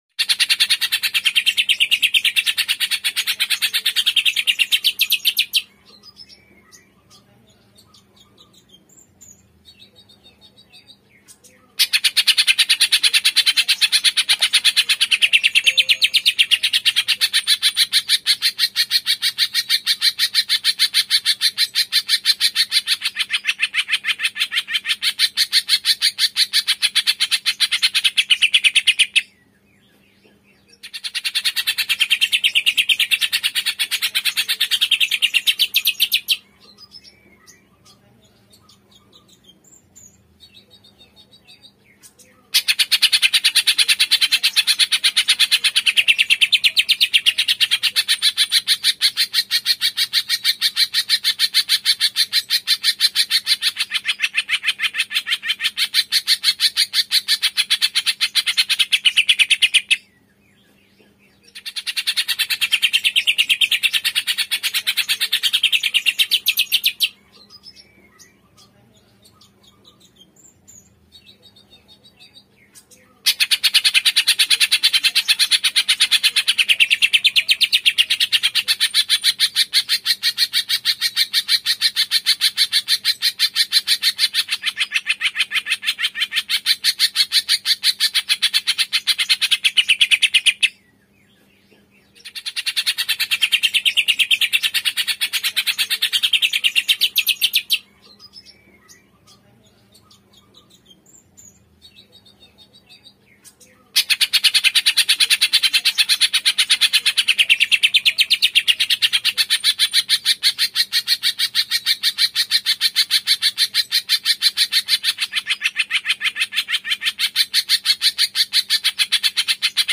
Suara Burung Cucak Ijo Mini Full Isian
Kategori: Suara burung
Keterangan: Unduh suara Cucak Ijo Mini full isian, Cucak Ijo Mini dengan tembakan Ijo Mini gacor dan melimpah. Dilengkapi dengan nembak panjang-panjang dan Cucak Ijo ngotot yang bongkar isian kasar, cocok untuk melatih burung agar lebih gacor dan bervariasi.
suara-burung-cucak-ijo-mini-full-isian-id-www_tiengdong_com.mp3